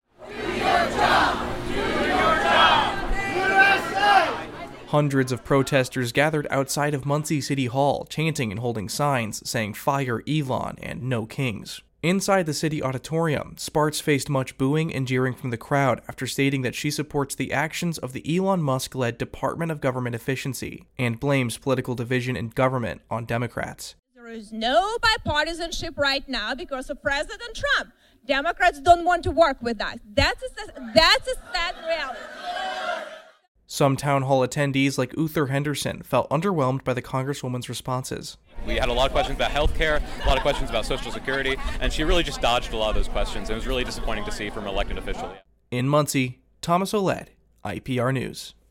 Listen in Popup Download MP3 Comment U.S. Rep. Victoria Spartz (R-Indiana) answers audience questions during a Saturday town hall meeting in Muncie.
Hundreds of protestors gathered outside of Muncie City Hall, chanting”Do your job!” yelling “USA!” and holding signs saying “Fire Elon” and “No kings.”
Inside the city auditorium, Spartz faced much booing and jeering from the crowd after stating she blames political division in government on Democrats.